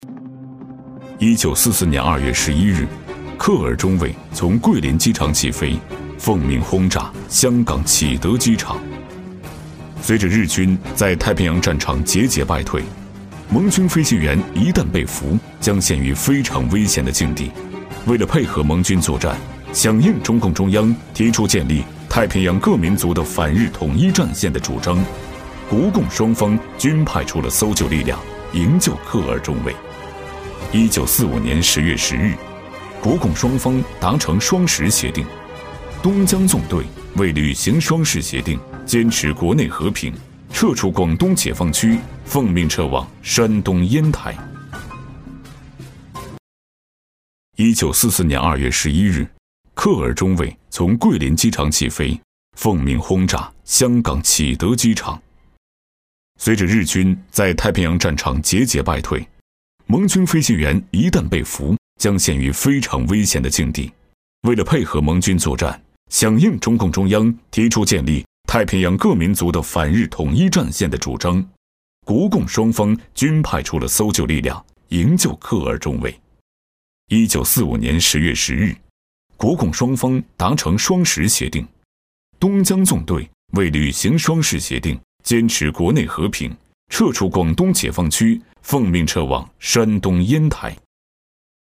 190男-沧桑大气
特点：大气浑厚 稳重磁性 激情力度 成熟厚重
历史专题——营救克尔中尉【紧迫感】.mp3